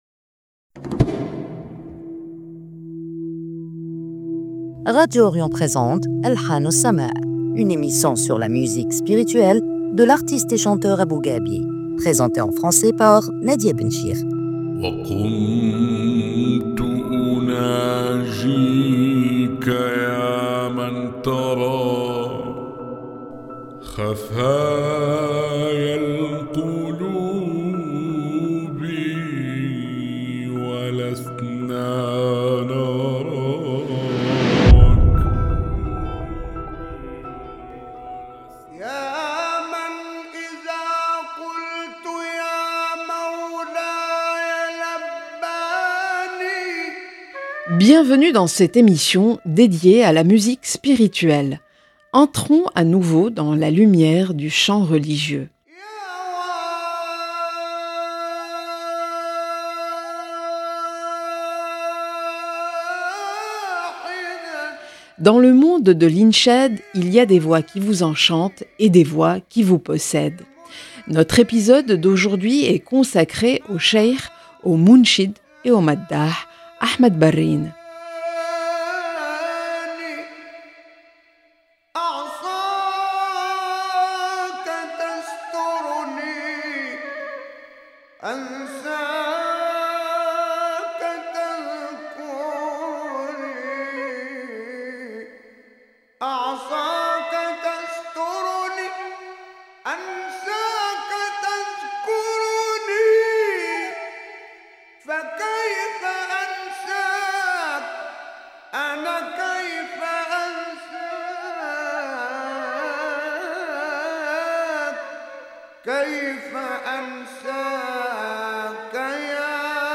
Une voix née de la terre du Saïd, qui a porté le madîh jusqu’aux sommets de la ferveur et de l’extase. Dans cet épisode, nous nous rapprochons du secret d’une voix qui a créé un phénomène inoubliable, dont l’écho traverse encore le temps.